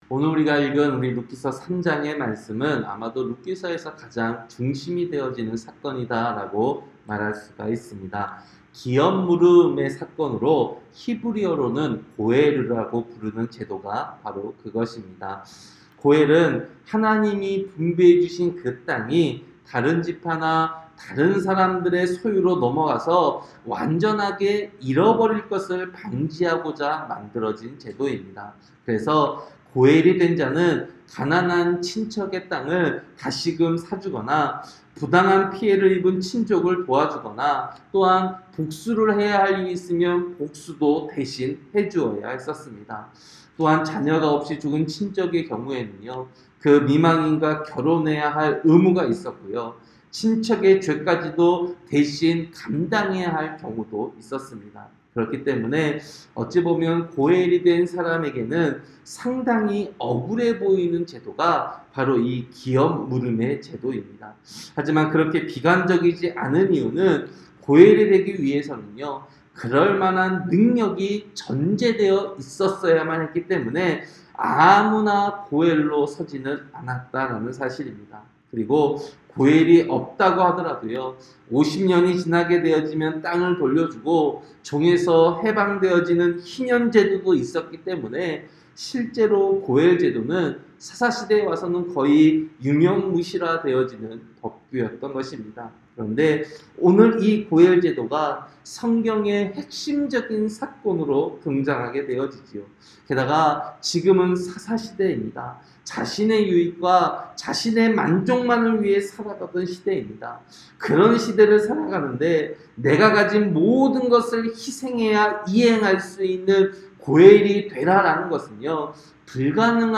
새벽설교-룻기 3장